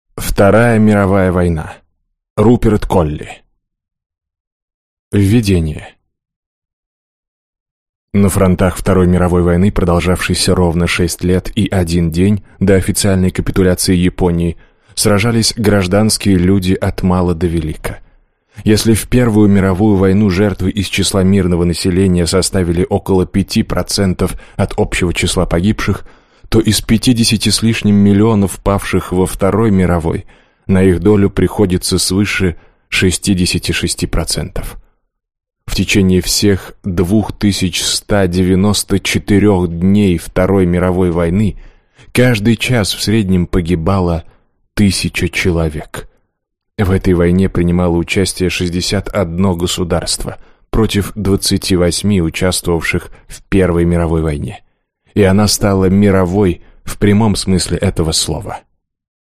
Аудиокнига Вторая мировая война | Библиотека аудиокниг